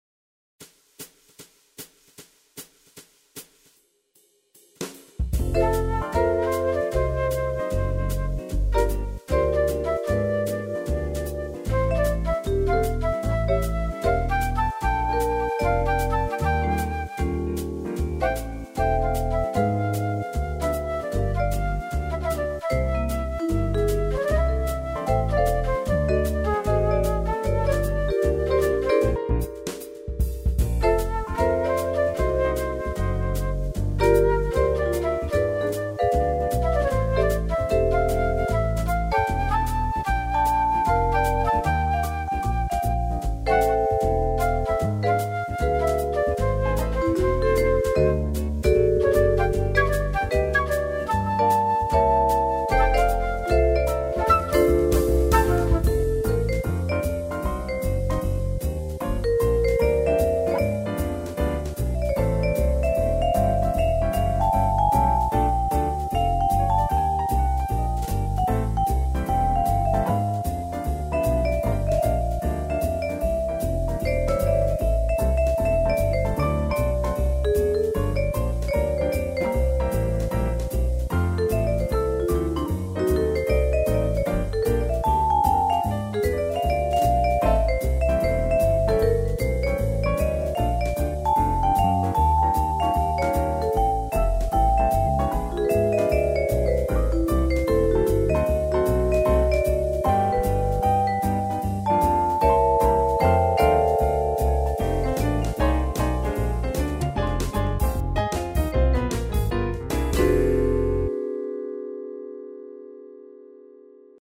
CD Instrumental